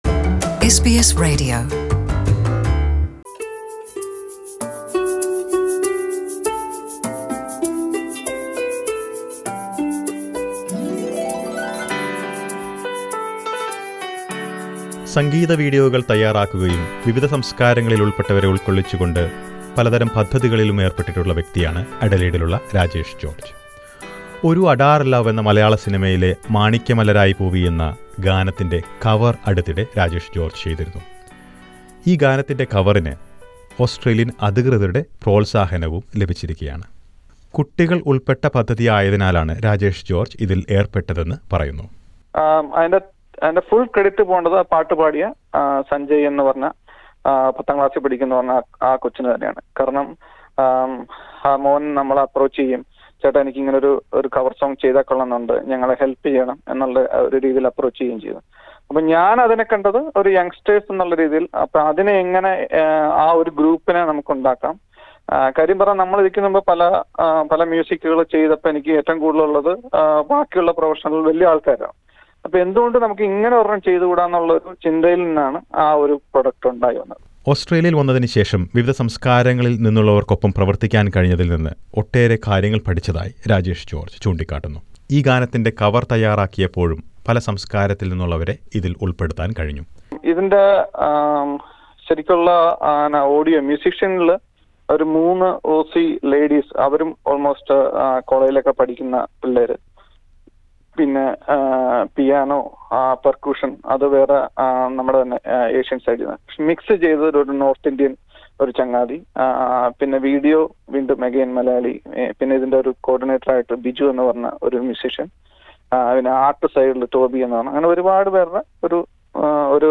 Their team was invited to the parliament of South Australia as a gesture of appreciation. Listen to the report above.